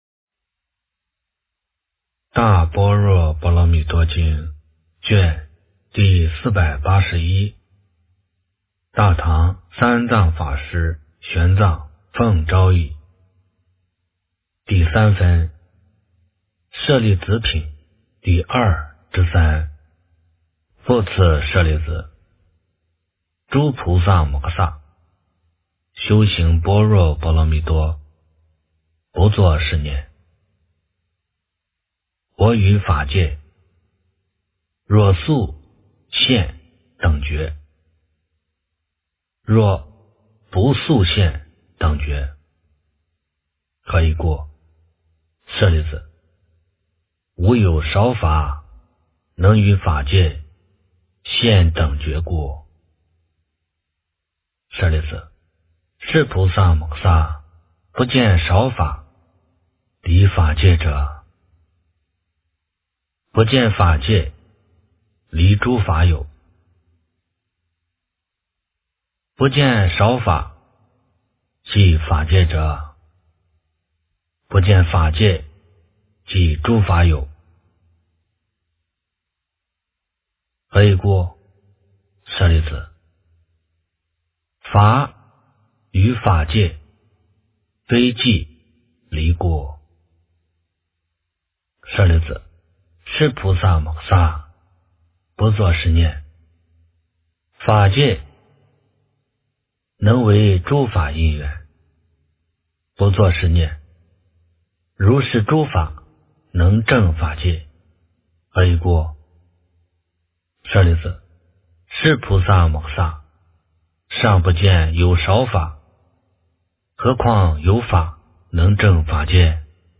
大般若波罗蜜多经第481卷 - 诵经 - 云佛论坛